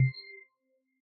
windowHide.ogg